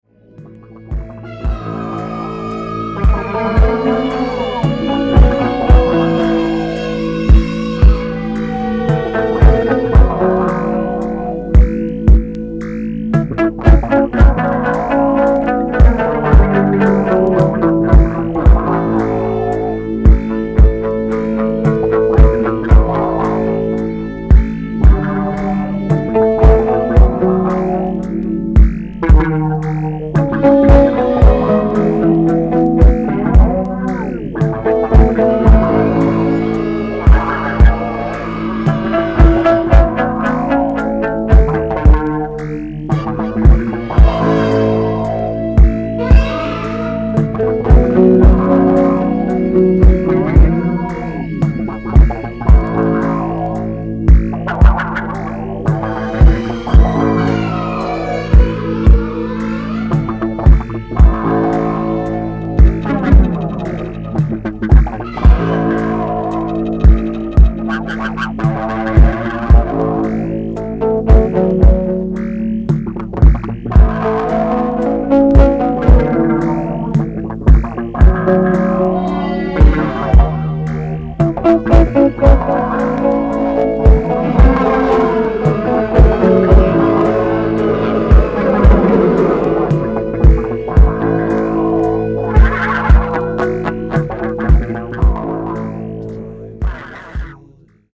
ポストパンク経由のむきだしのミニマリズムに、サイケデリックな浮遊感を伴うシンセやギターのひずんだ轟音を注ぎ込んだ全6曲。
キーワード：ミニマル　宅録　脱線パンク